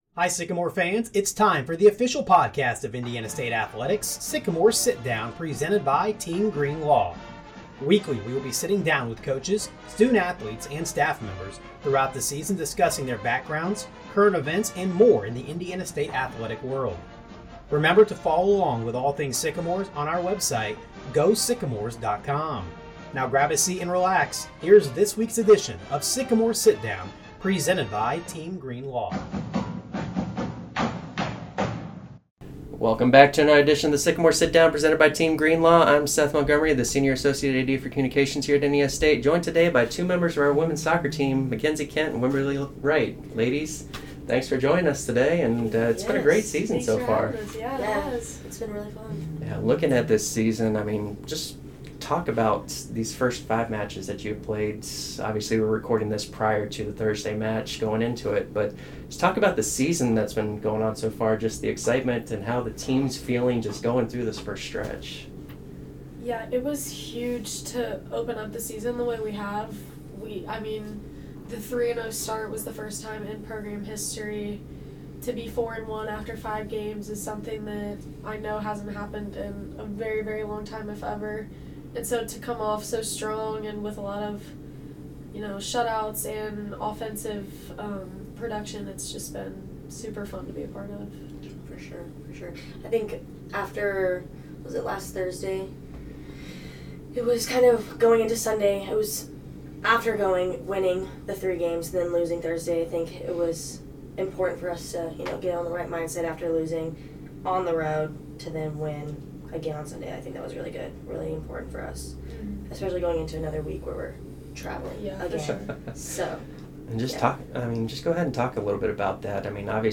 Sycamore Podcast Central Page The weekly podcast will feature various Sycamore administrators, coaches, athletes, alumni, and more as we sit down with the Sycamores every week.